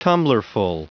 Prononciation du mot tumblerful en anglais (fichier audio)
Prononciation du mot : tumblerful